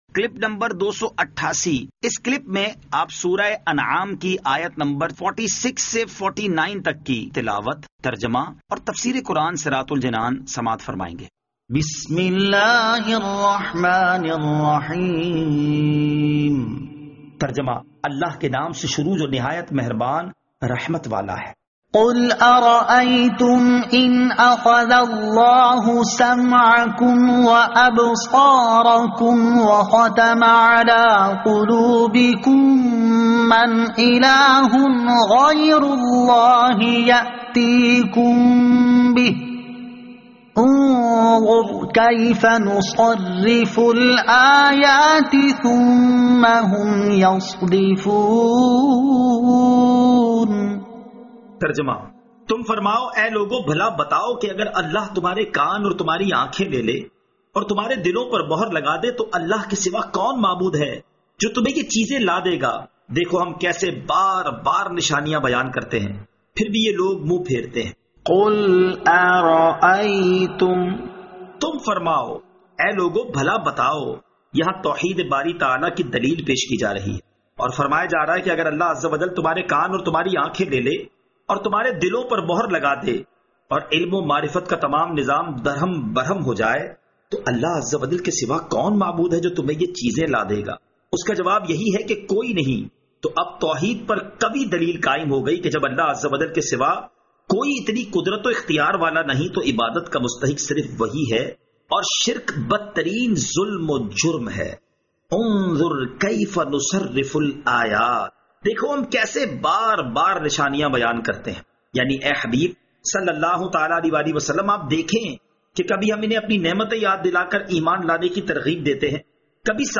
Surah Al-Anaam Ayat 46 To 49 Tilawat , Tarjama , Tafseer